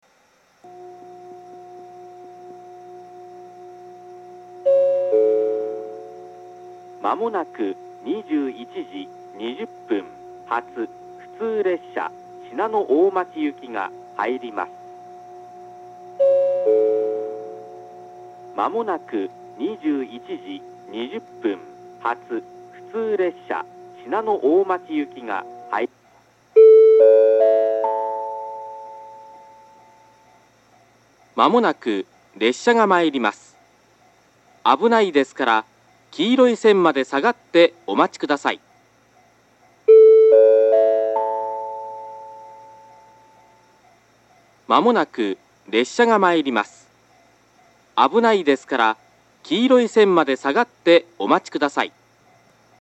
１番線下り接近放送 接近予告放送が流れている途中から流れ始めています。